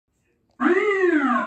Air Horn Efeito Sonoro: Soundboard Botão
Air Horn Botão de Som